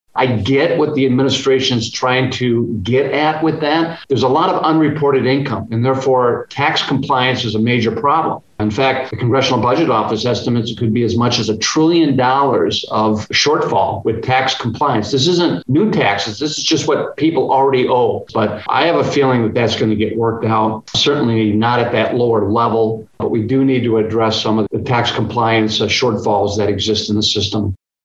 US Representative Ron Kind explains.